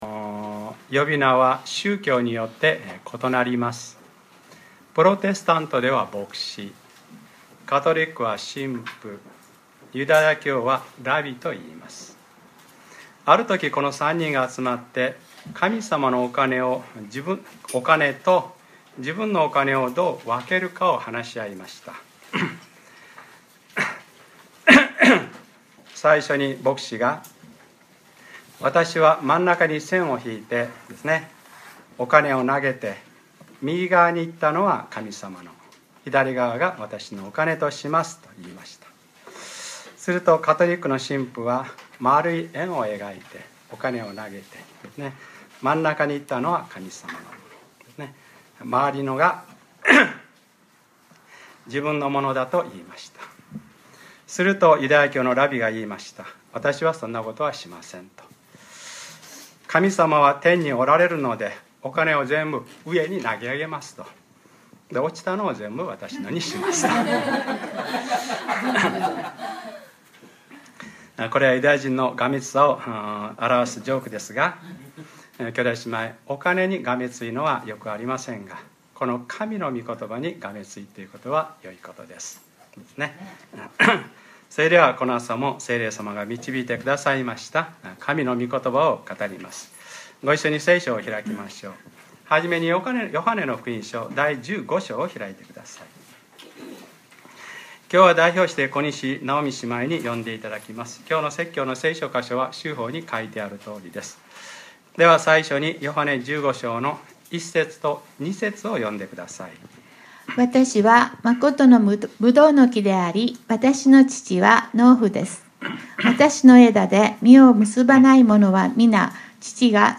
2013年6月23日(日）礼拝説教 『わたしにとどまっていなければ』